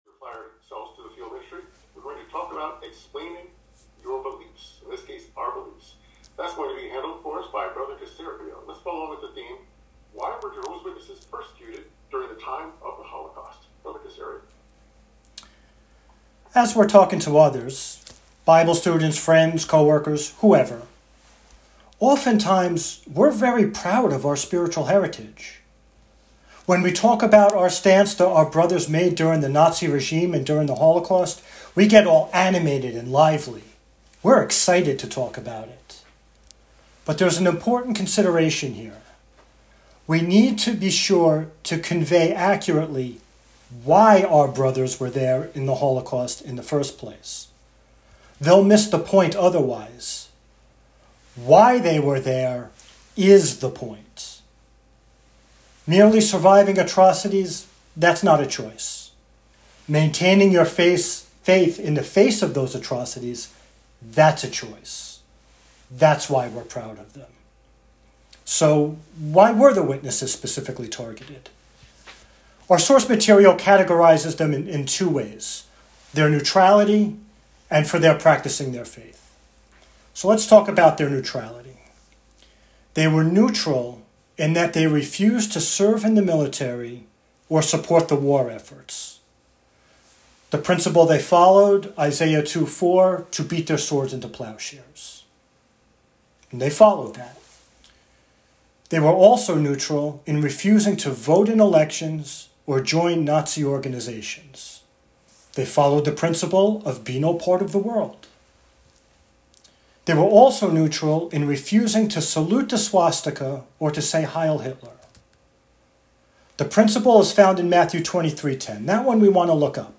Given from home via Zoom.
(5 min.) Talk. ijwfq 57 ¶5-15—Theme: Why Were Jehovah’s Witnesses Persecuted During the Time of the Holocaust?